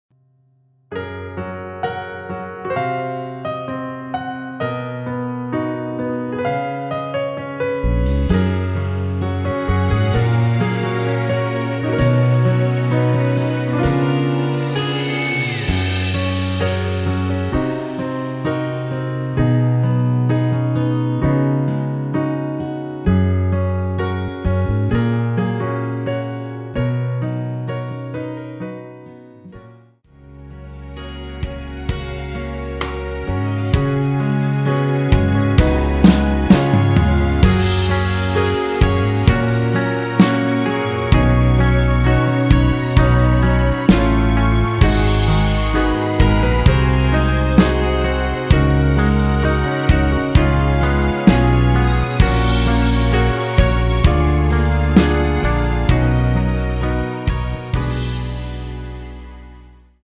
원키에서(+3)올린 MR입니다.
여성분이 부르실수 있는 키로 제작 하였습니다.(미리듣기 참조)
F#
앞부분30초, 뒷부분30초씩 편집해서 올려 드리고 있습니다.